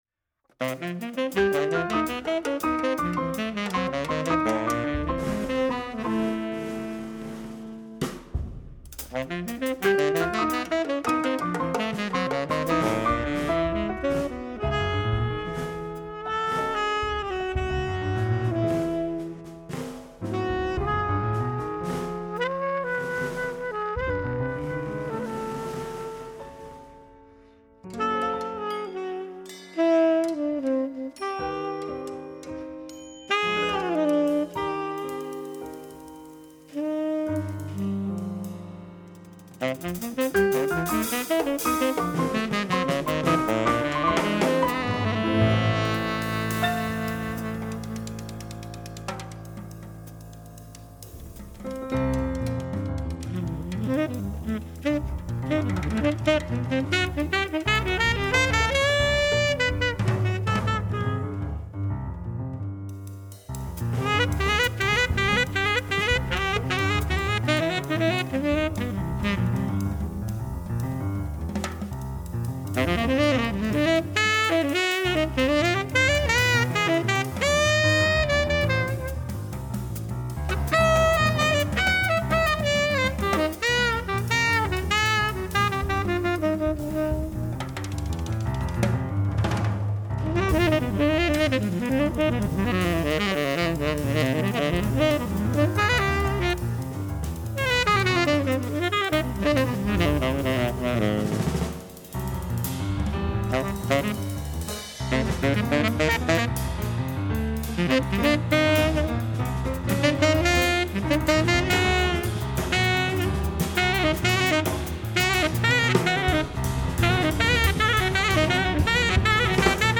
結構辛口でハイテンションな曲が印象的。
piano
soprano and tenor saxophone
drums and percussion